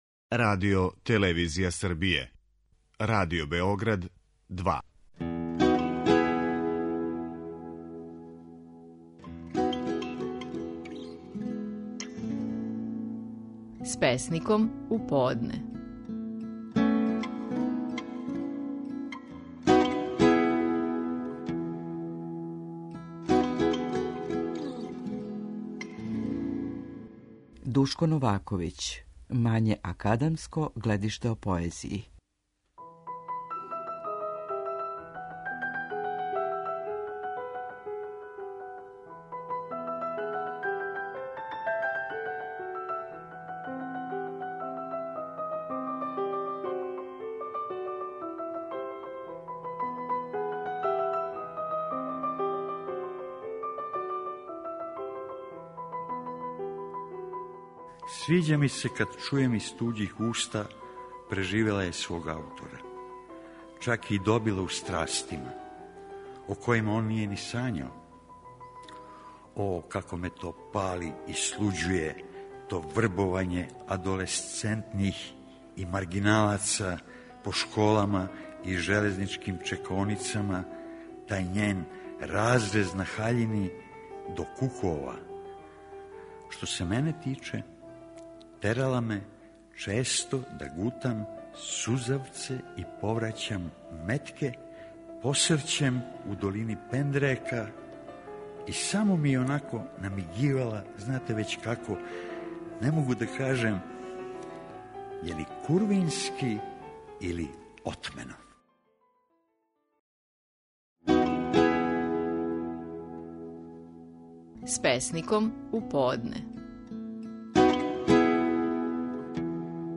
Стихови наших најпознатијих песника, у интерпретацији аутора.
Душко Новаковић говори песму „Мање академско гледиште о поезији".